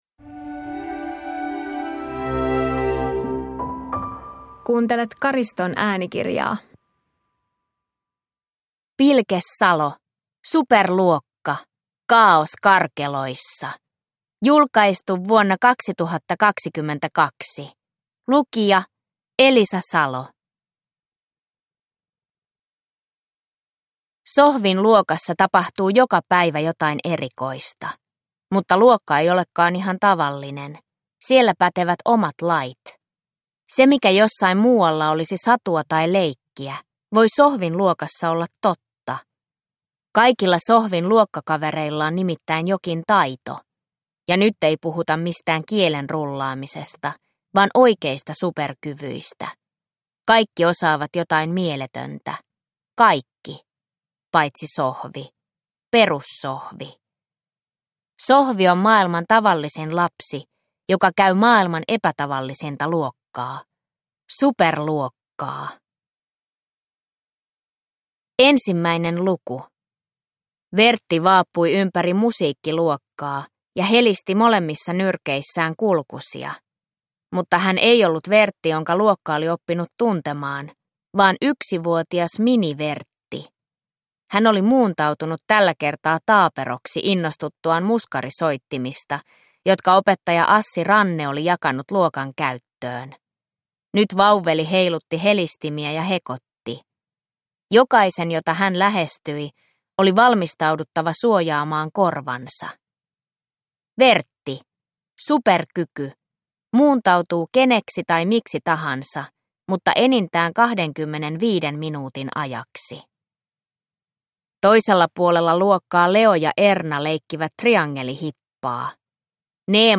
Kaaos karkeloissa – Ljudbok – Laddas ner